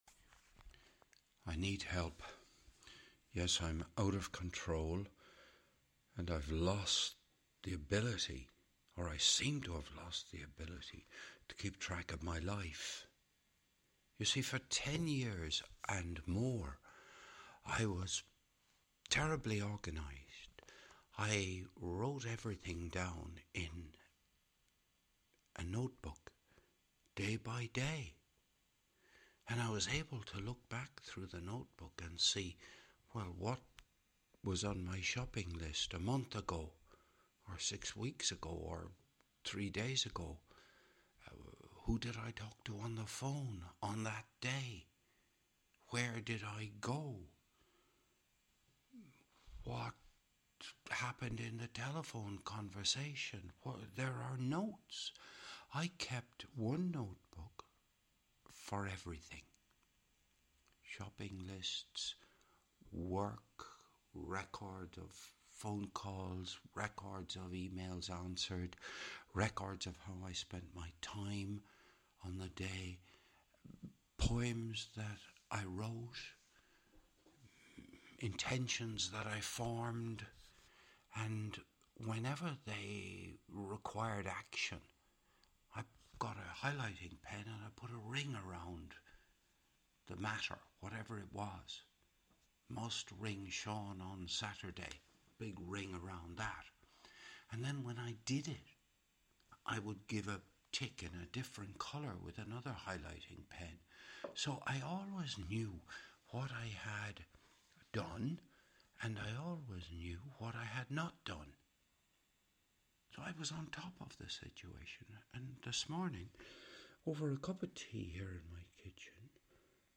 this was recorded on the morning of Thursday 13th of February 2025 - in my kitchen